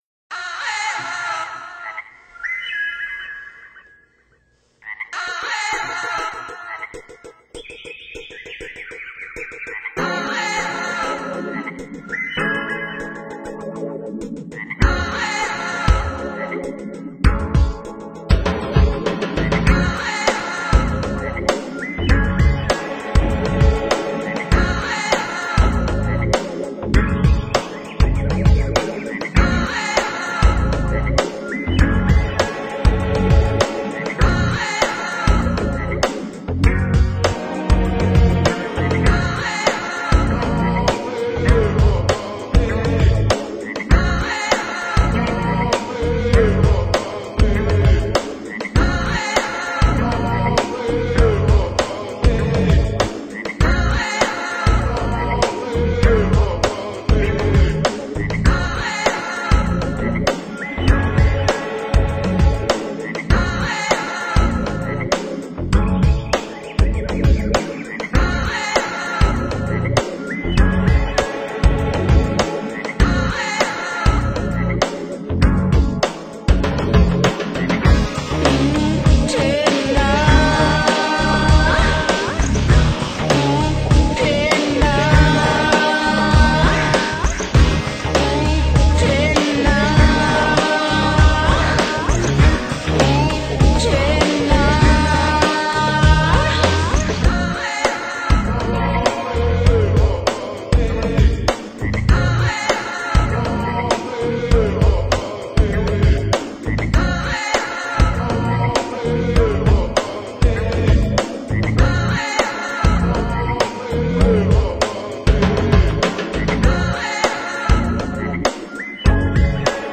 专辑语言：纯音乐
架子鼓的振响，在森林里回荡。